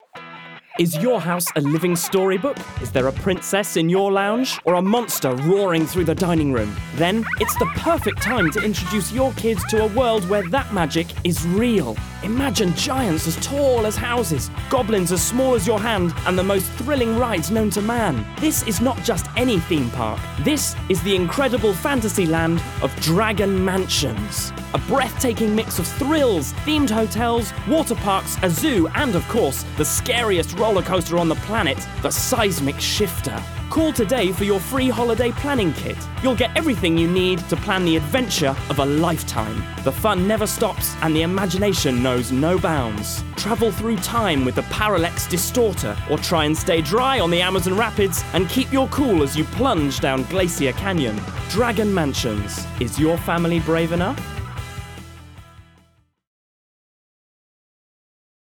Teenager, Young Adult, Adult
Has Own Studio
british rp | natural
COMMERCIAL 💸
warm/friendly
Theme_park_commercial.mp3